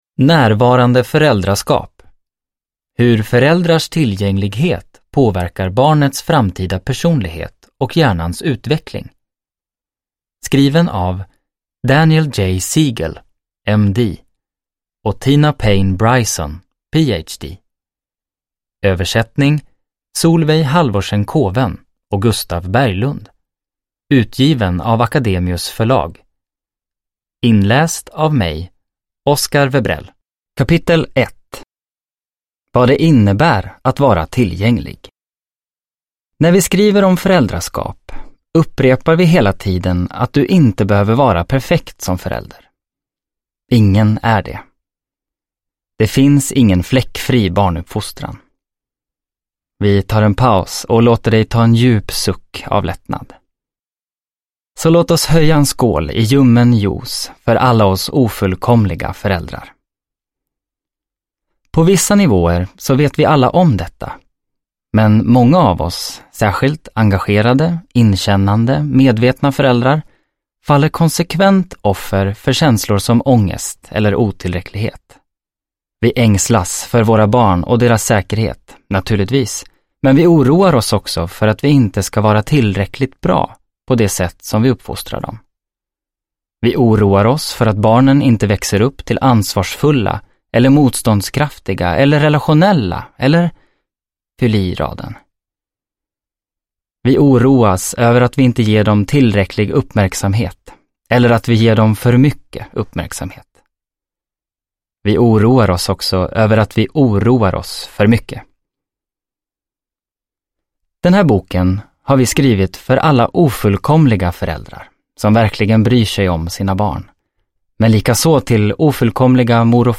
Ljudbok 209 kr